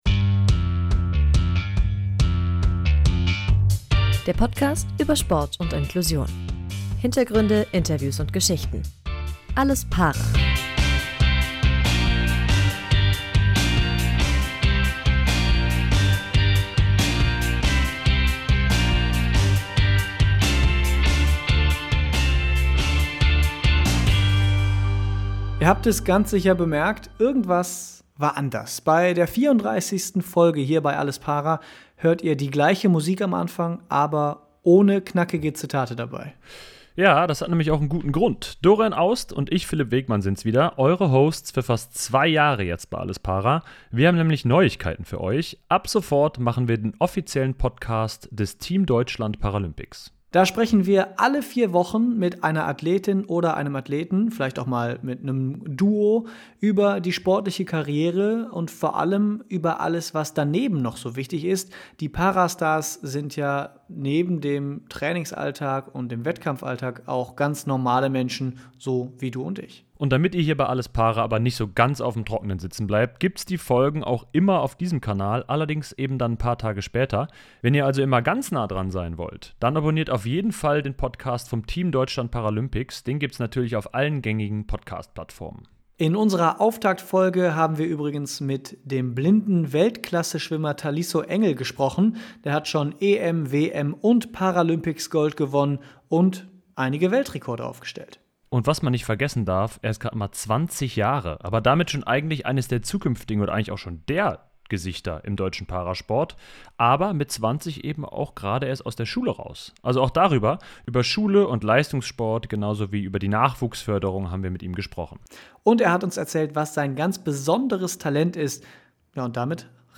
Zur Folge: Er ist Paralympicssieger und Weltrekordhalter: Schwimmer Taliso Engel ist der Vorzeige-Athlet der jungen deutschen Para-Sport Generation. Beim Team Deutschland Paralympics Podcast sprechen wir mit dem sehbehinderten Nürnberger über seinen steilen Aufstieg, Eliteschulen im Sport und was sein verborgenes Talent ist.